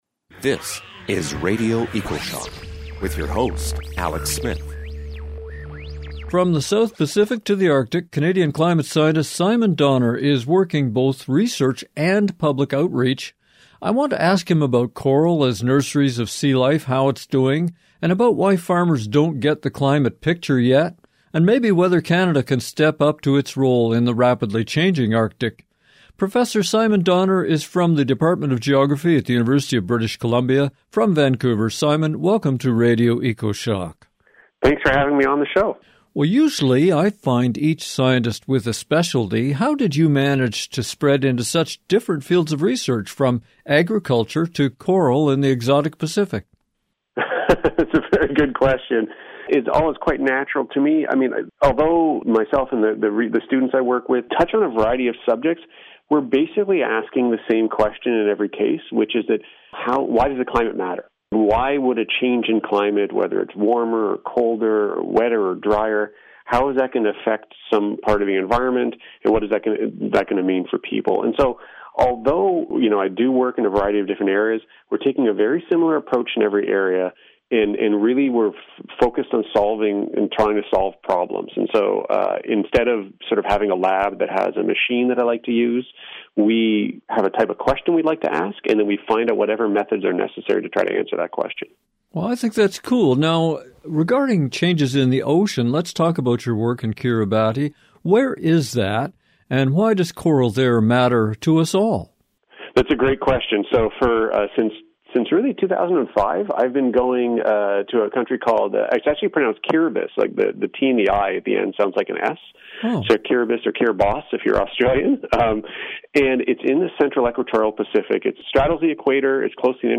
Then we check in with Canadian climate scientist